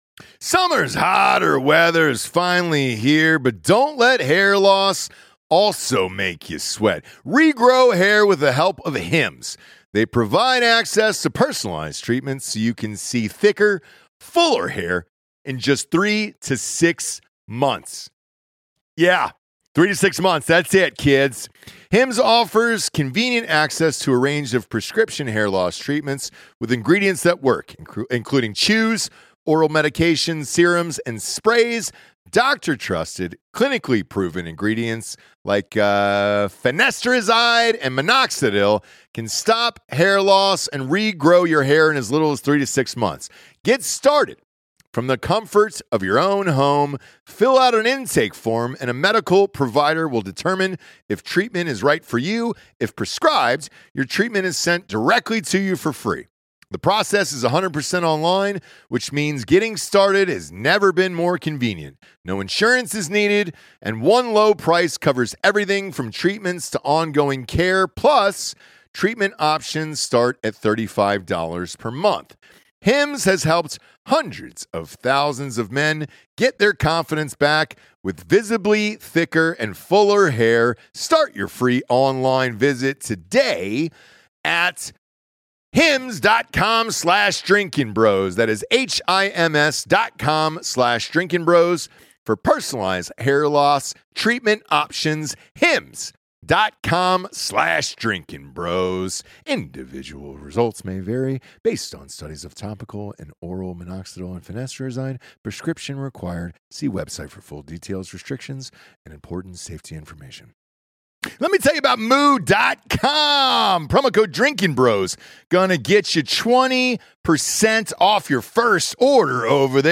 at the Canelo fight in a Jerry World in Dallas.